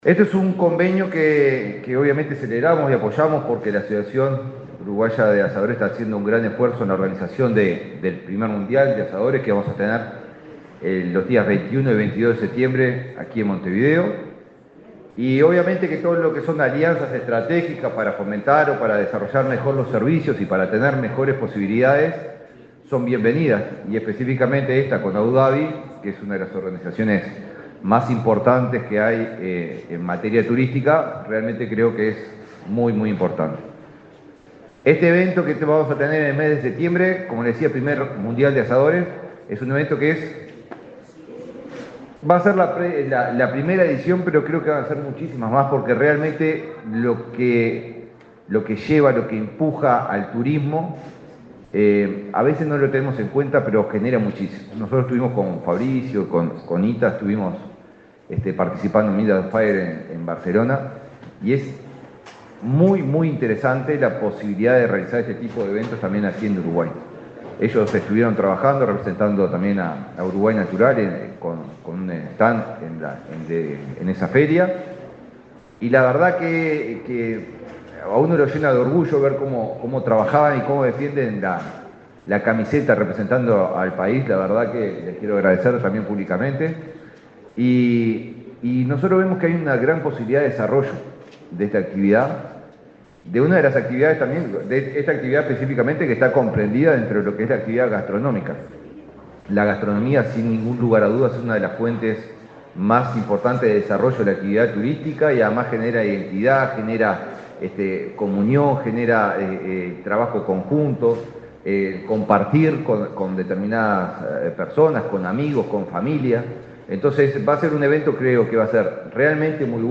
Palabras del ministro de Turismo, Eduardo Sanguinetti
Palabras del ministro de Turismo, Eduardo Sanguinetti 06/08/2024 Compartir Facebook X Copiar enlace WhatsApp LinkedIn El ministro de Turismo, Eduardo Sanguinetti, participó, este martes 6, en el lanzamiento del Torneo Mundial de Asadores, que se realizará los días 21 y 22 de setiembre en el Espacio Modelo de Montevideo.